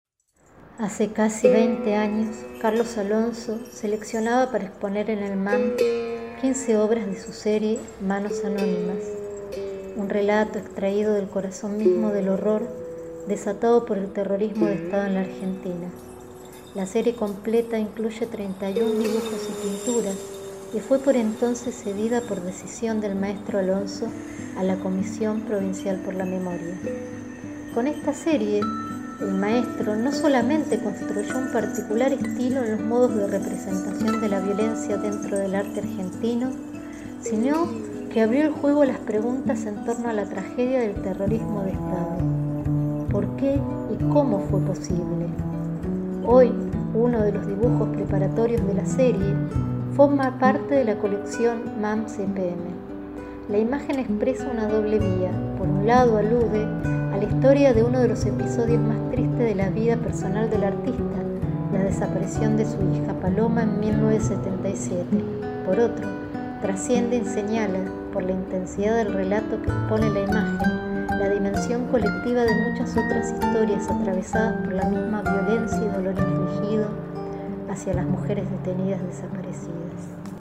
Audioguía adultos